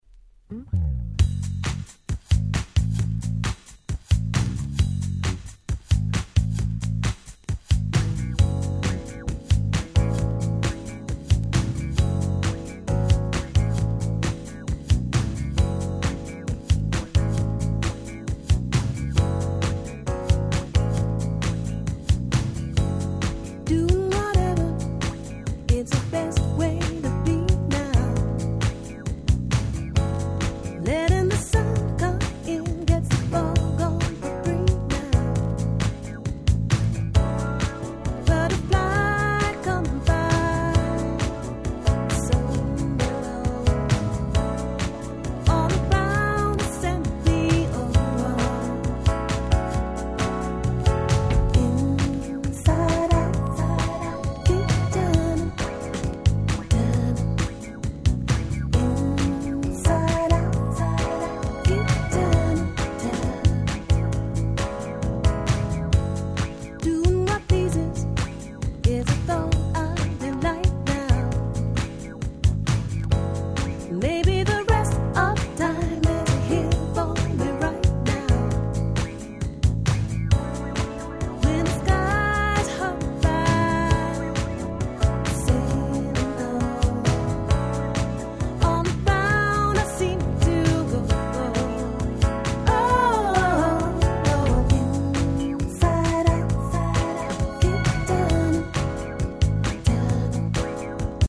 Neo Soulな感じのまったりとした素晴らしいUK Soul。